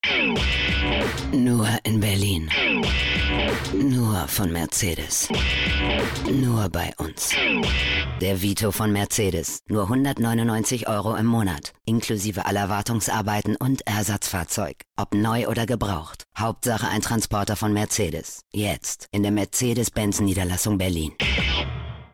markant, dunkel, sonor, souverän
Mittel minus (25-45)
Audio Drama (Hörspiel)